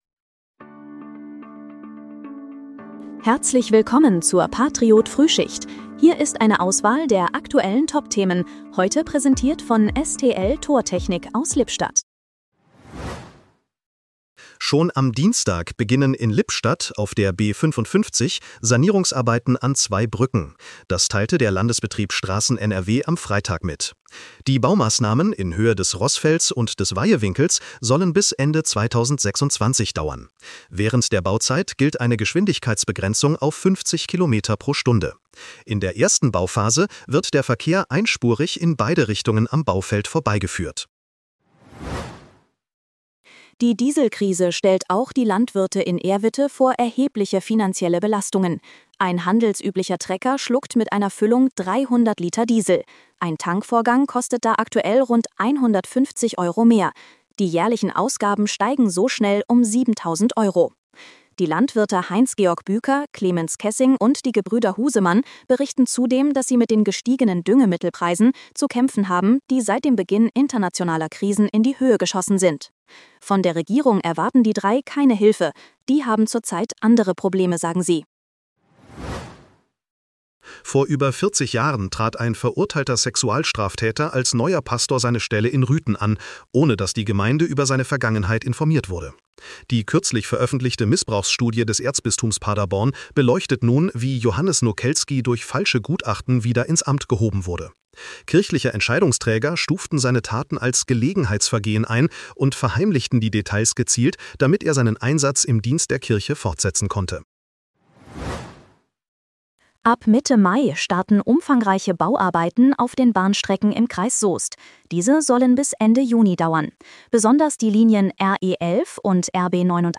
Dein morgendliches News-Update
mit Hilfe von Künstlicher Intelligenz.